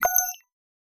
Futuristic Sounds (28).wav